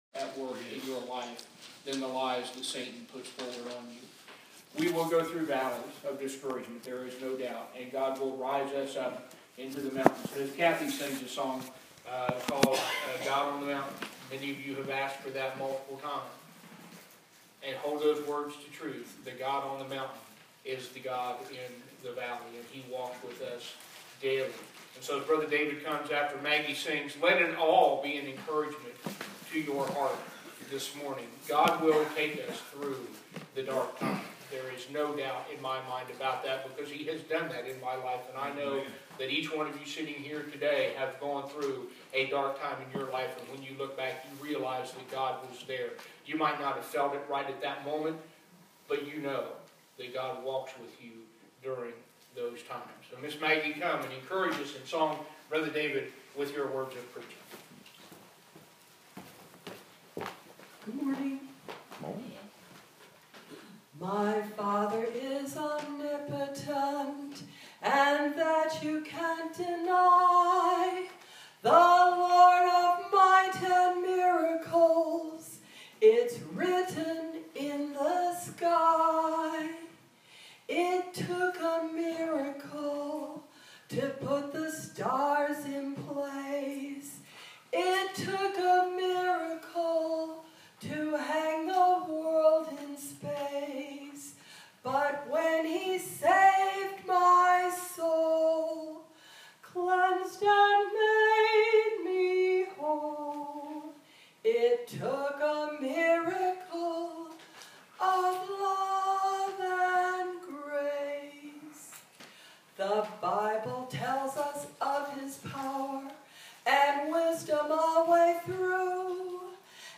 Preached on the morning of January 15 2017 at Riverview, 798 Santa Fe Pike, Columbia TN